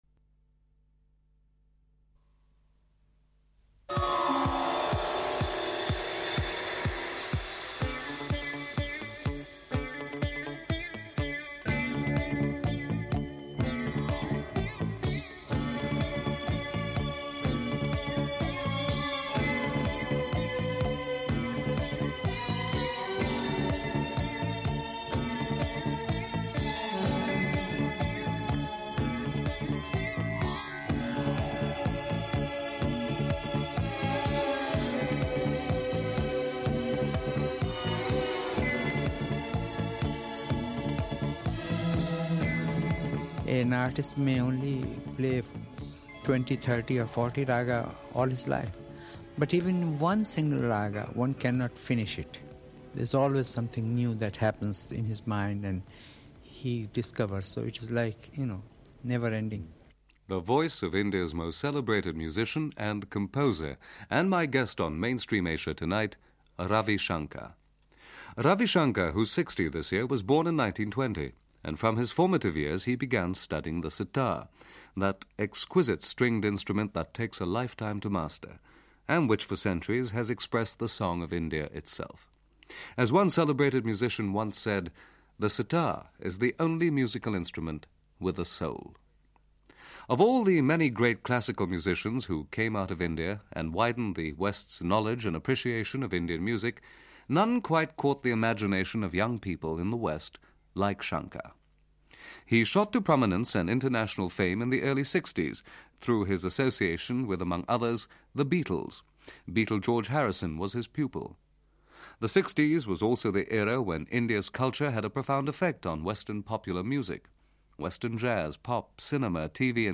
To mark the 60th birthday of India’s most famous composer and musician, Ravi Shankar, Radio Netherlands devoted a special edition of its weekly Asia magazine programme “Mainstream Asia” to the great sitar artist. Ravi Shankar’s fame burst beyond the borders of Indian when the Beatles discovered him in the early 1960’s, and George Harrison became one of his many pupils.